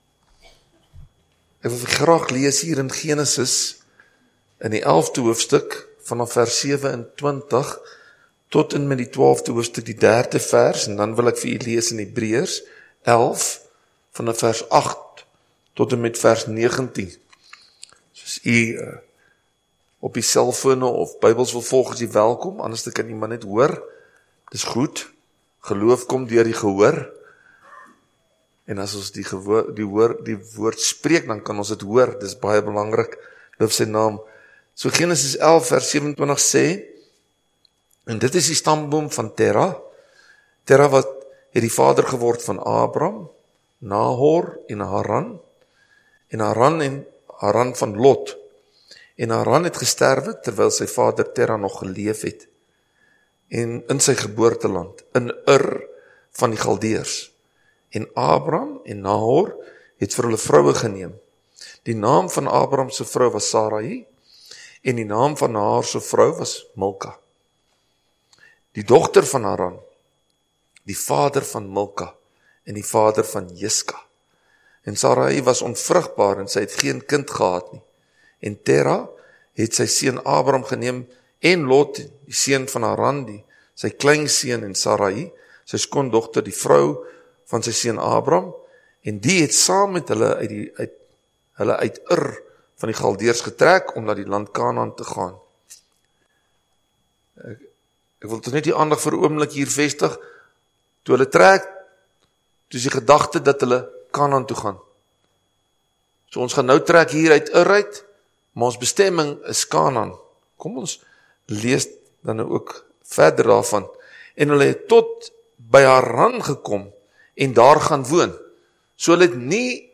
Predikant